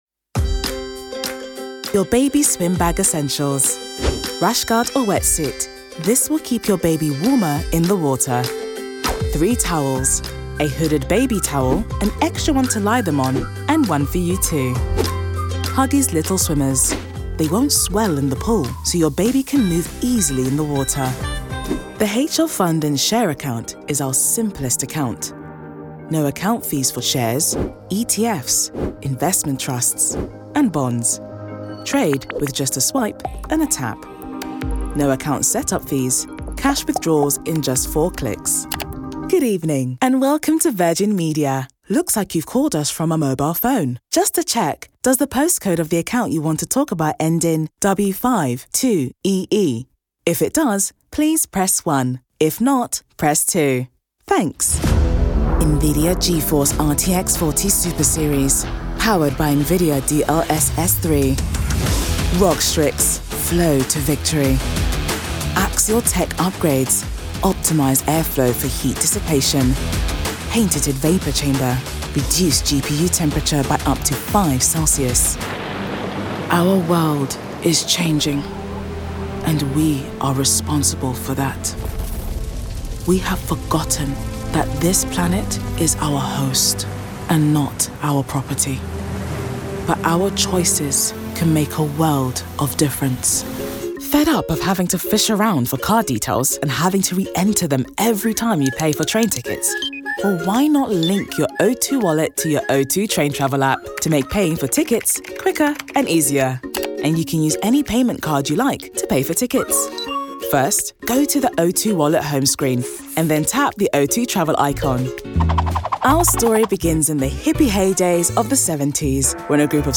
The Smooth and Calm, with Resonance.
London, Multicultral Language English, RP
With a voice that is not only resonant, I believe I am versatile in range and can adapt to requests which includes warmth, a touch of earthiness, alongside being smooth and calm but can be assertive, grounded and trustworthy too.